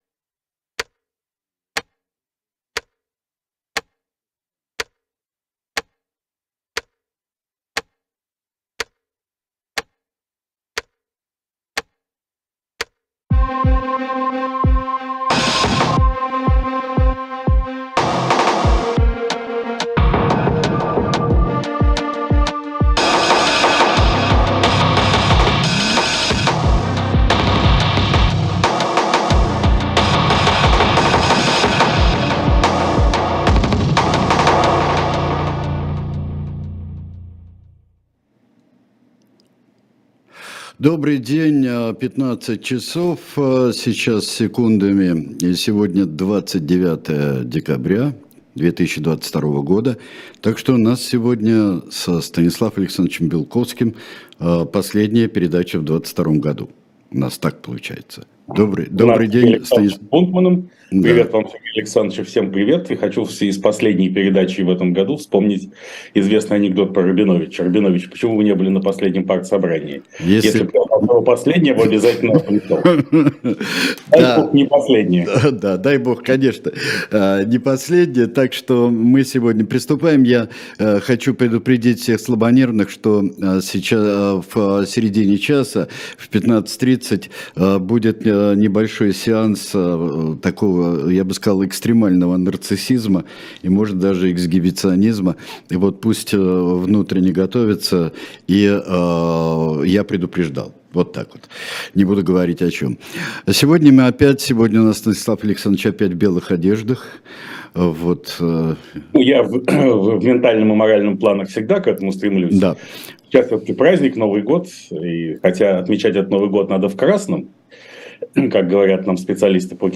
Ведет эфир Сергей Бунтман...